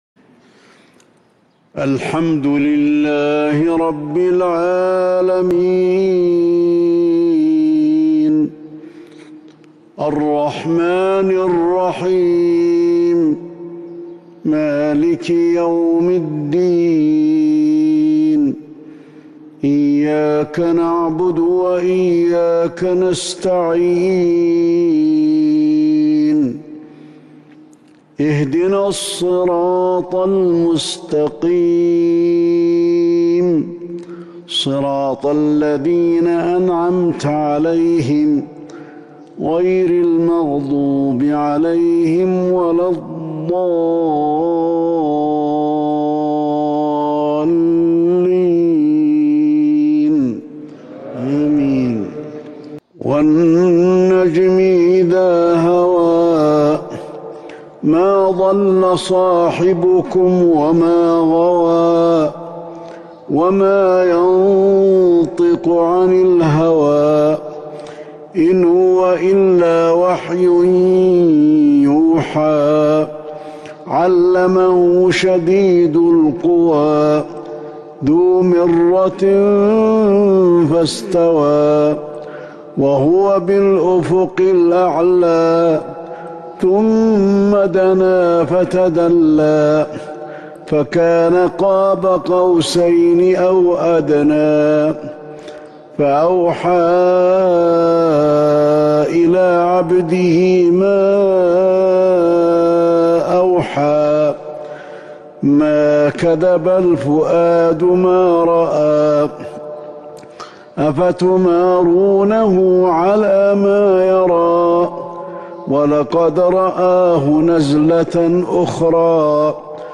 صلاة الفجر من سورة النجم 4-5-1442 Fajr prayer from Surat An-Najm 12/19/2020 > 1442 🕌 > الفروض - تلاوات الحرمين